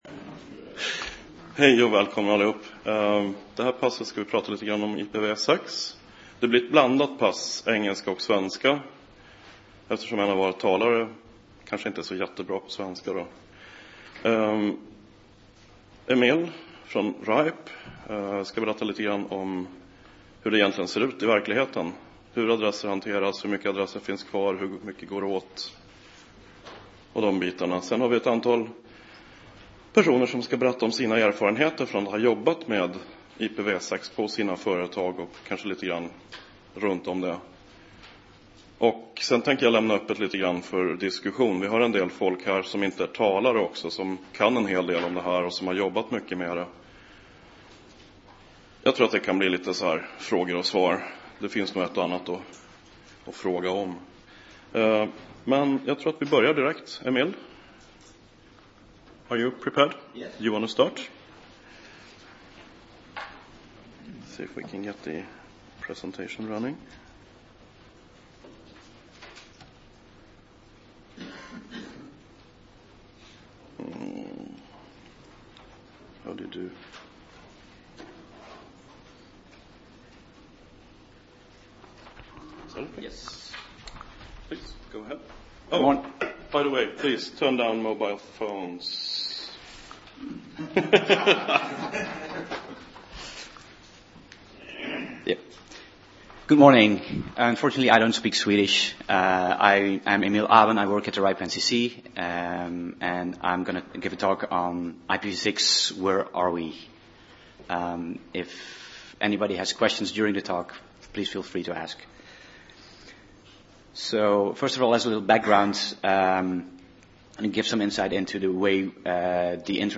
IPv6 har tagit fart under 2010 men ännu är det långt kvar till målet. Seminariet presenterar ett antal aktörer som har infört IPv6 i sina nätverk och diskuterar metoder och resultat.